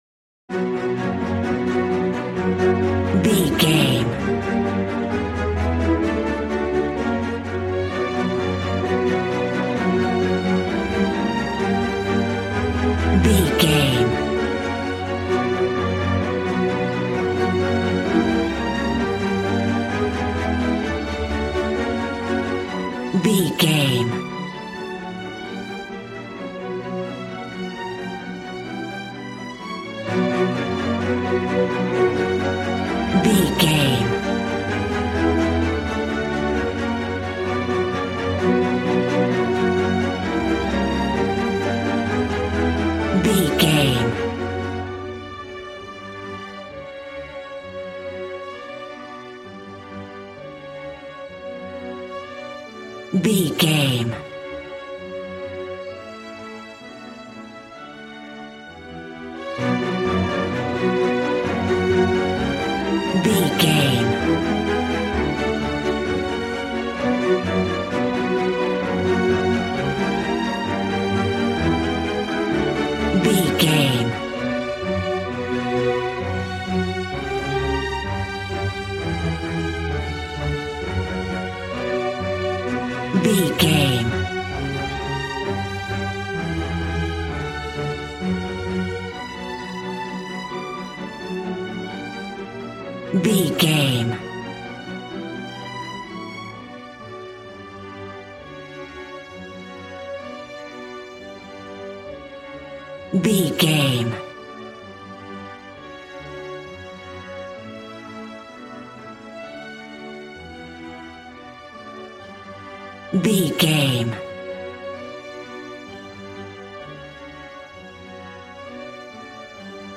Regal and romantic, a classy piece of classical music.
Aeolian/Minor
Fast
regal
strings
brass